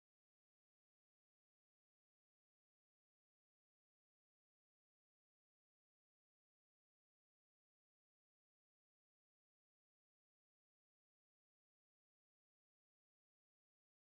ניצלו בנס - תיעוד נפילת רקטה מוקדם יותר היום בתל אביב.